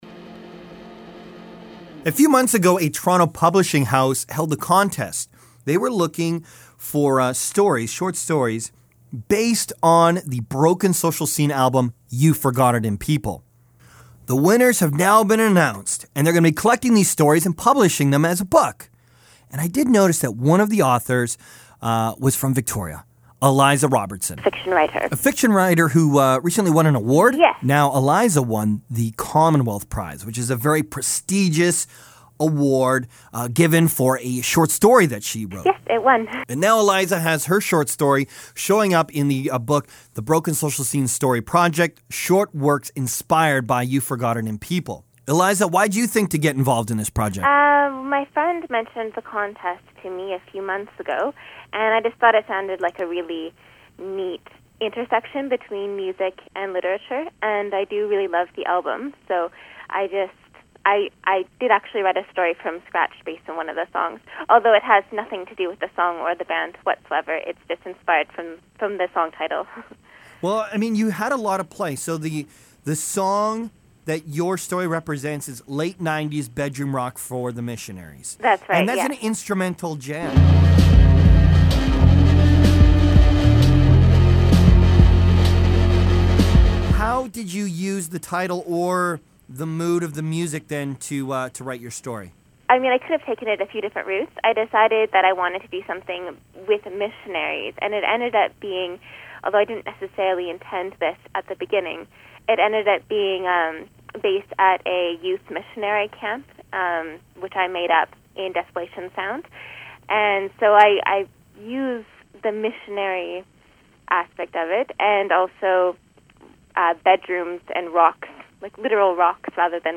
Here is our talk.